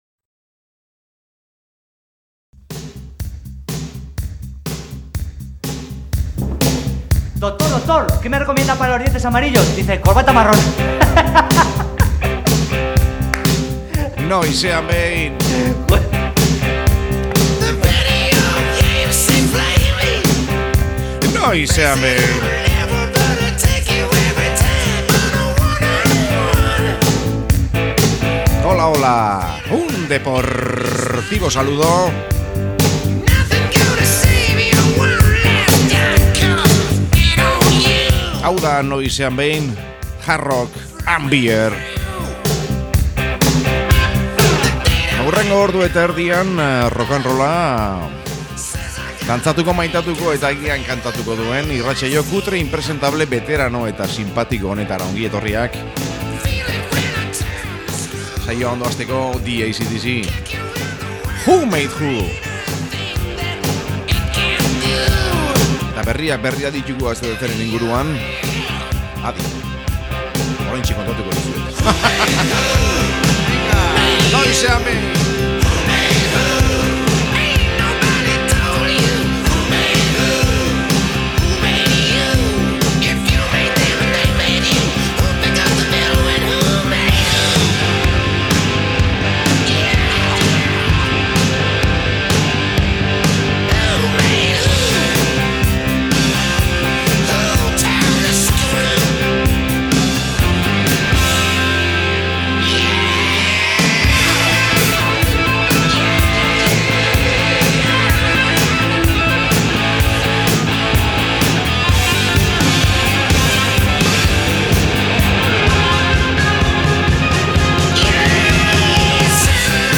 Ez gara ez gaizki ibili gaurkoan, bluesetik jebira eta gero infinitura eraman agituen bidaia paregabean.